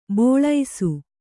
♪ bōḷaisu